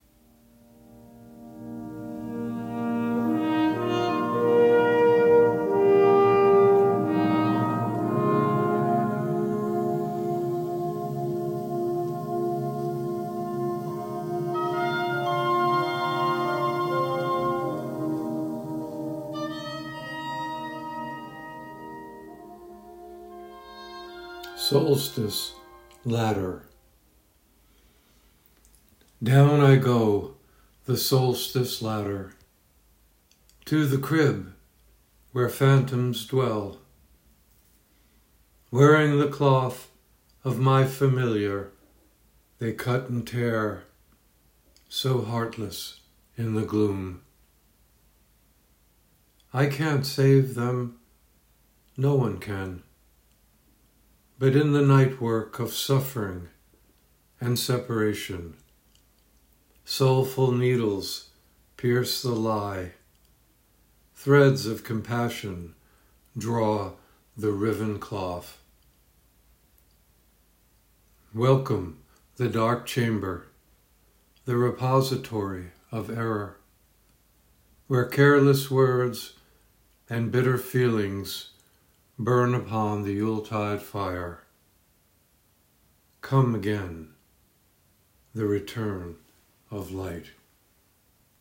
Reading of “Solstice Ladder” with music by Jean Sibelius